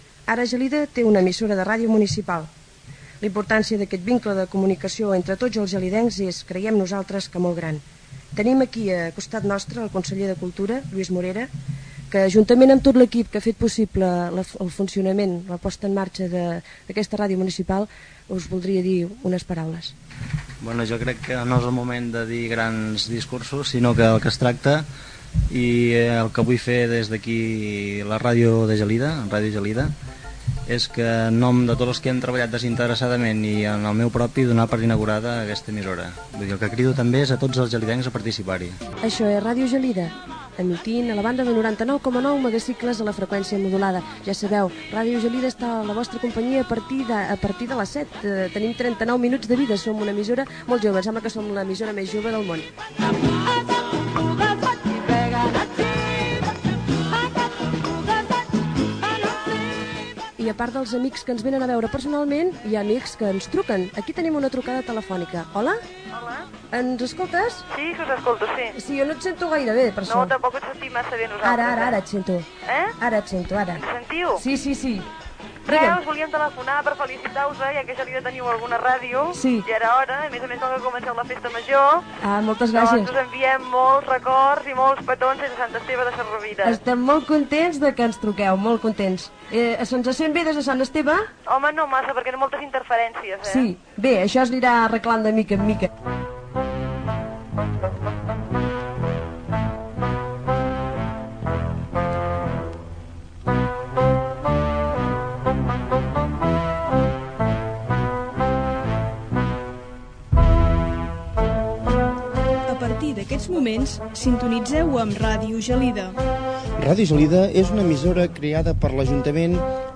Emissió inaugural. Paraules del regidor de cultura Lluís Morera, identificació, trucada telefònica, identificació.
Tancament de l'emissió amb la sintonia de les Emissores Municipals de Catalunya (EMUC)
FM